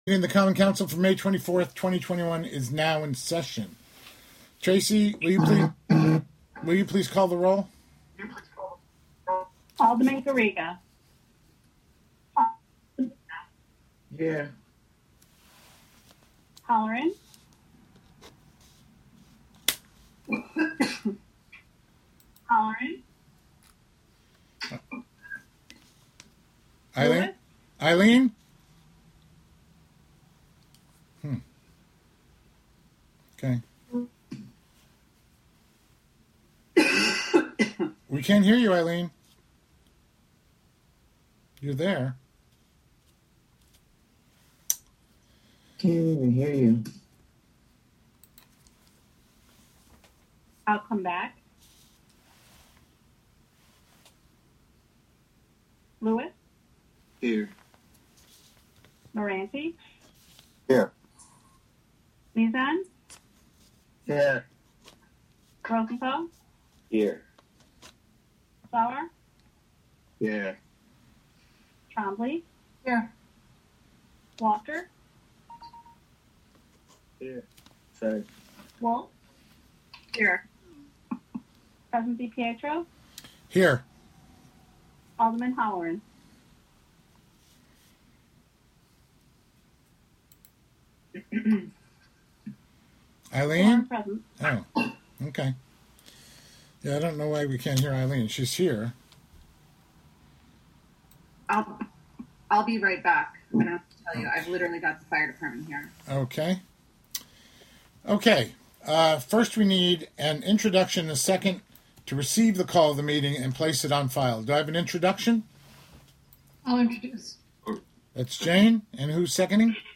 Live from the City of Hudson: Hudson Common Council Special Meeting (Audio)